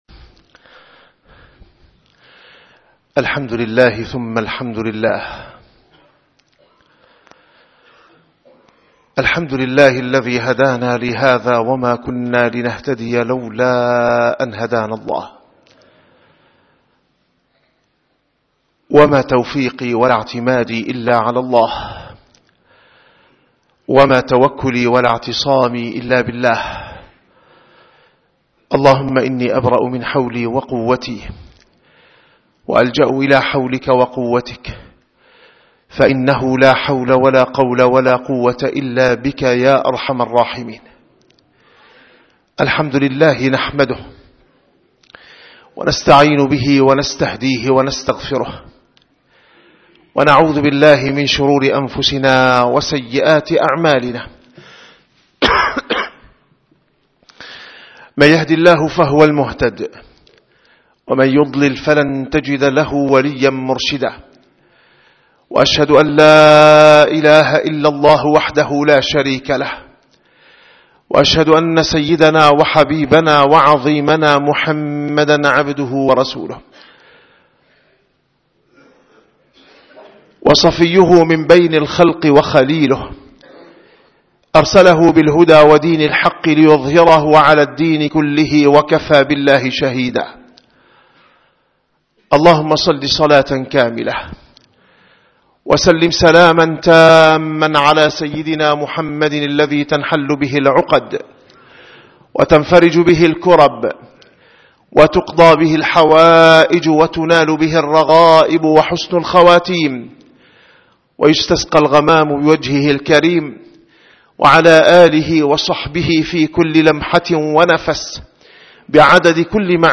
- الخطب - أداء الحقوق بمعرفة الواجبات